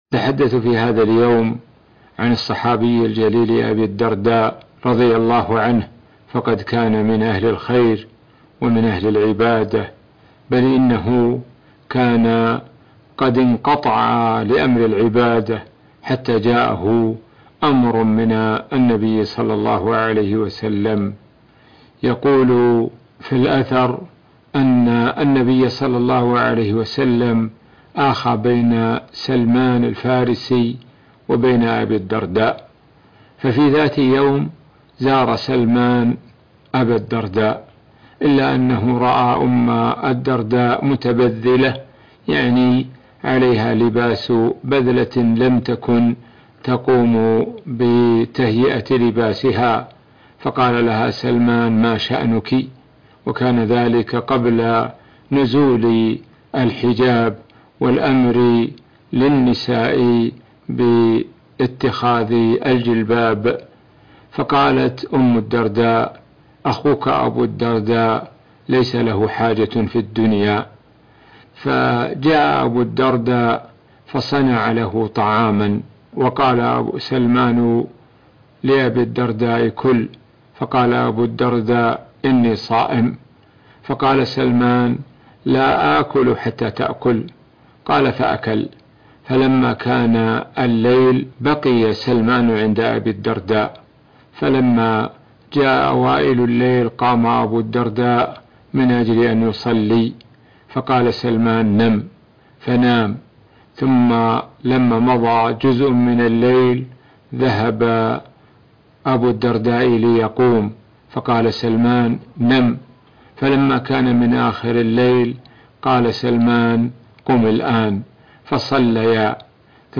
قصة الصحابي الجليل الملقَّب بحكيم الأمة أبو الدرداء الأنصاري يرويها الشيخ د .سعد الشثري - الشيخ سعد بن ناصر الشثري